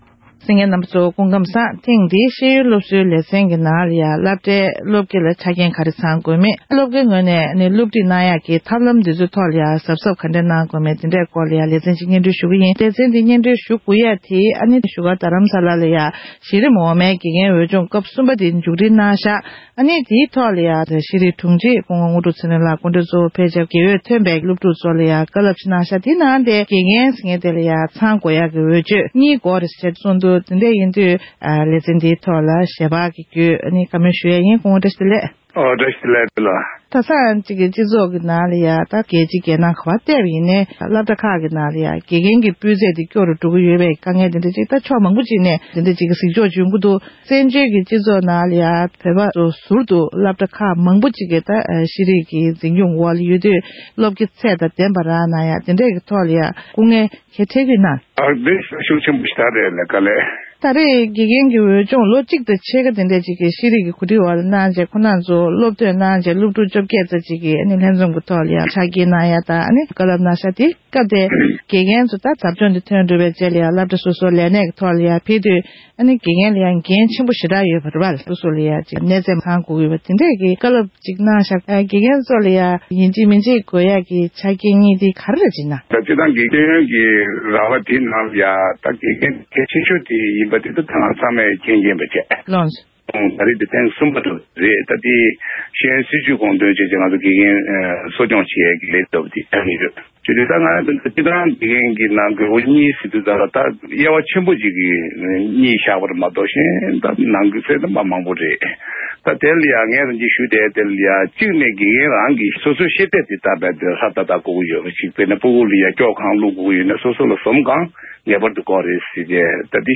གནས་འདྲི་ཞུས་པར་གསན་རོགས་གནང་།།